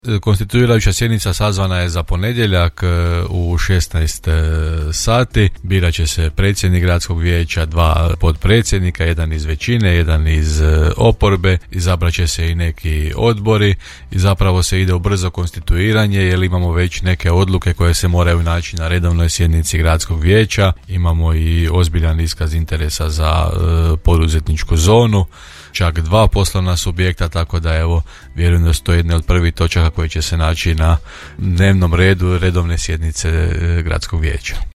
Gradonačelnik Janči je u emisiji Gradske teme još jednom zahvalio građanima na ukazanom povjerenju za još jedan mandat te dodao kako će se u ponedjeljak, 26. svibnja održati Konstituirajuća sjednica Gradskog vijeća Grada Đurđevca;